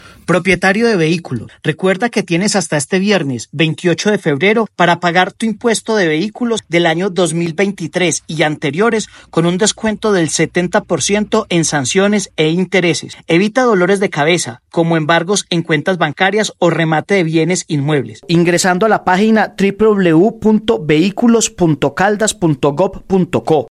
Jhon Alexander Alzate Quiceno, secretario de Hacienda de Caldas